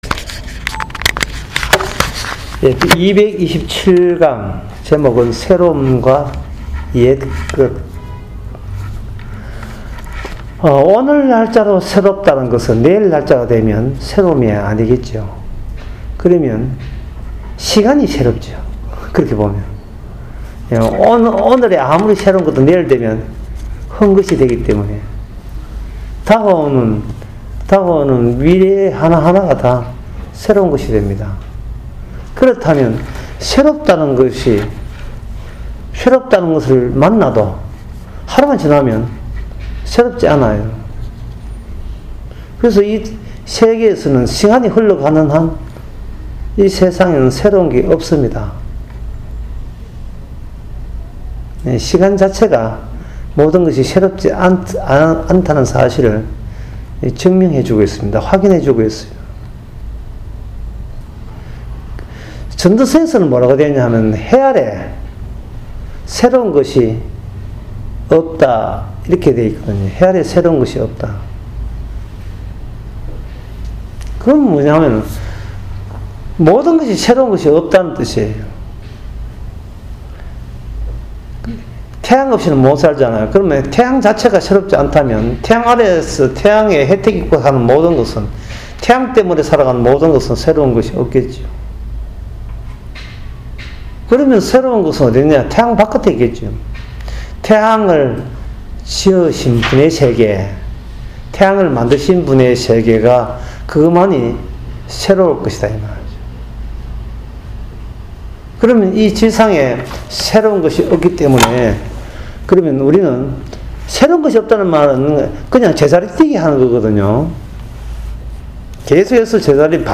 유튜브 강의